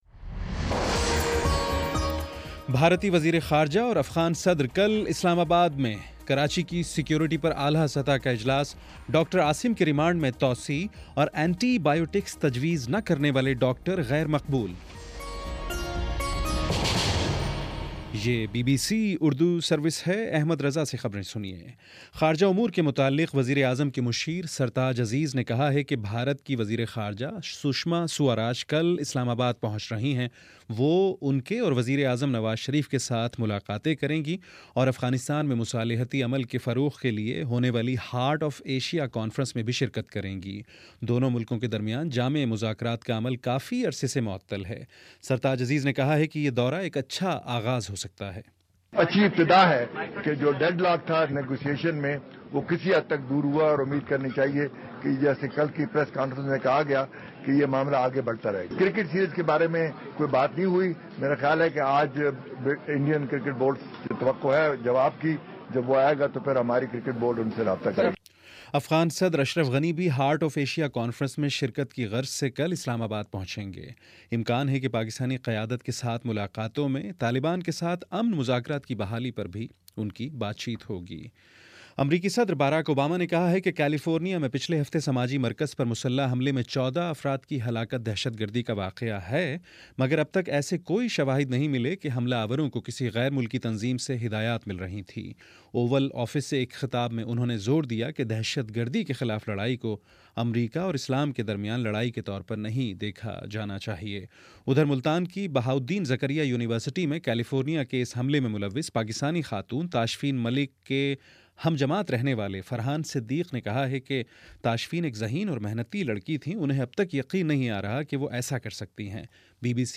دسمبر 07: شام سات بجے کا نیوز بُلیٹن